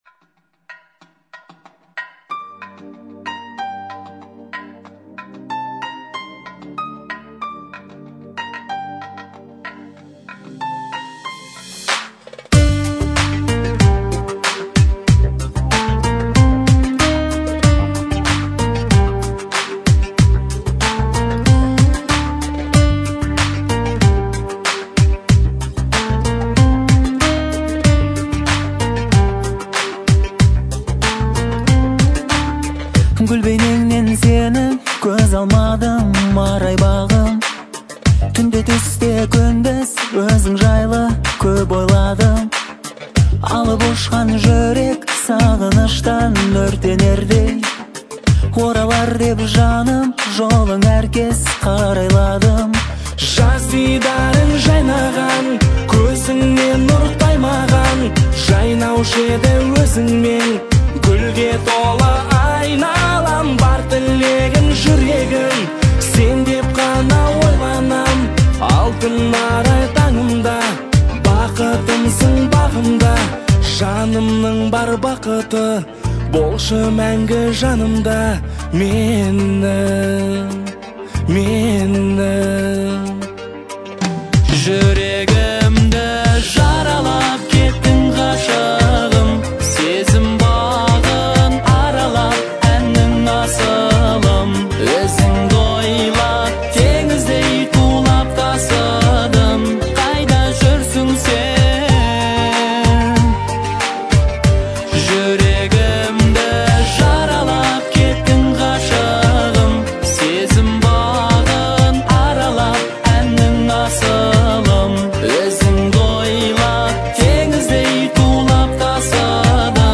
это трогательный казахский балладный трек в жанре поп.